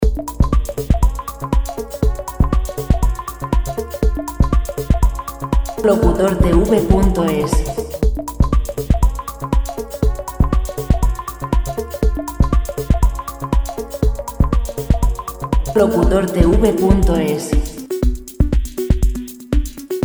Royalty-free Chillout background music